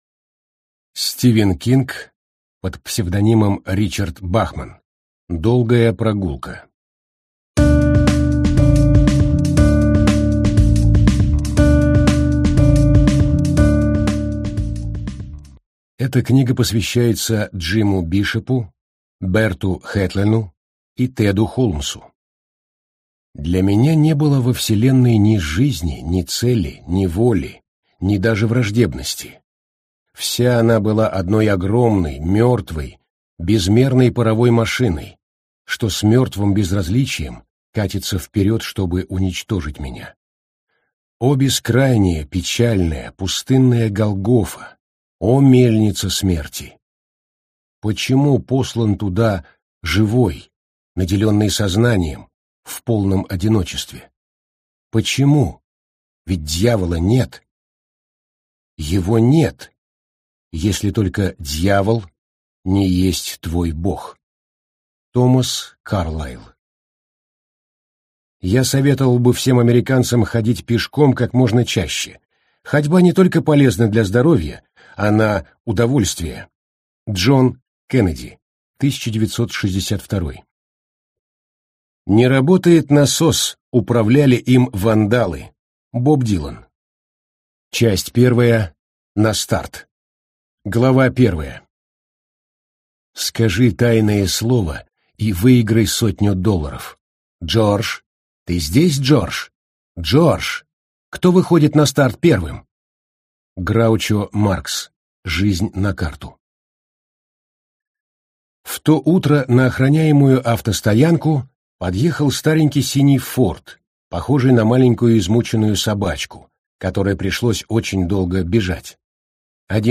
Аудиокнига Долгая Прогулка. Бегущий человек | Библиотека аудиокниг